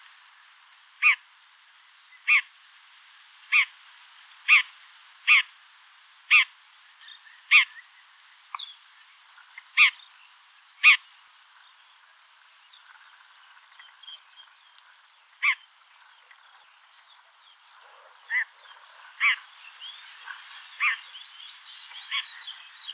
Lanius collurio - Red-backed shrike - Averla piccola
- SEX/AGE: unknown - COMMENT: these nasal calls are a bit more sparrow like than those of the previous recording. Background: wind noise and orthoptera stridulation.